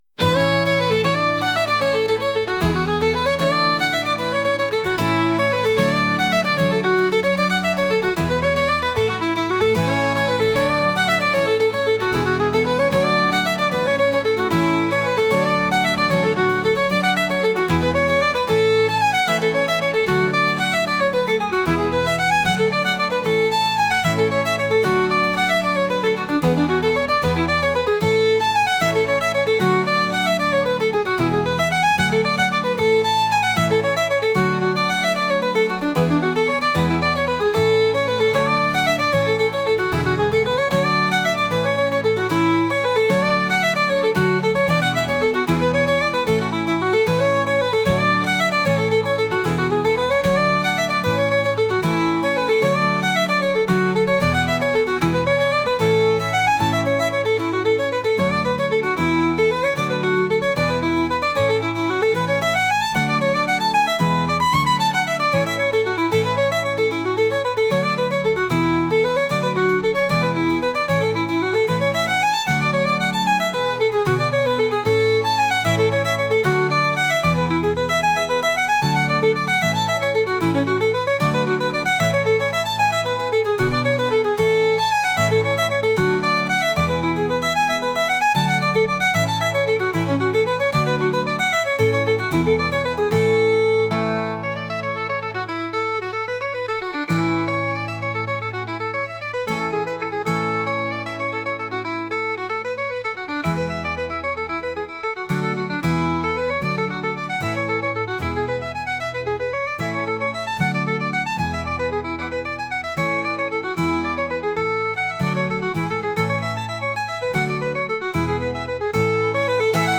お祭りのようなケルト音楽です。